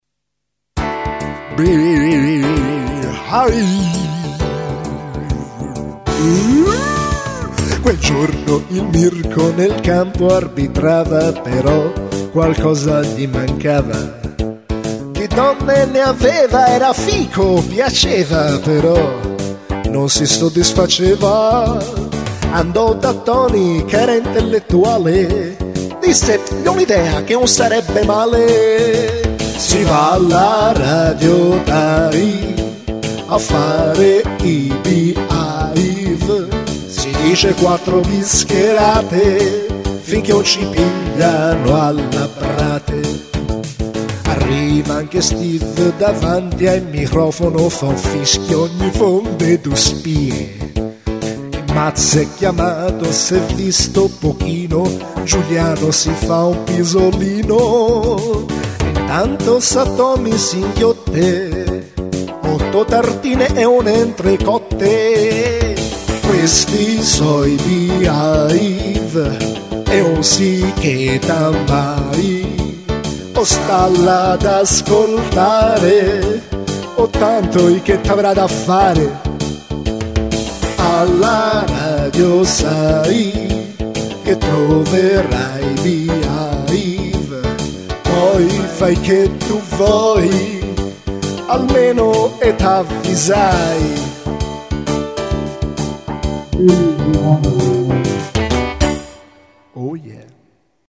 Una sigla per il programma radiofonico
Scervellato cantautore per spensierati conduttori!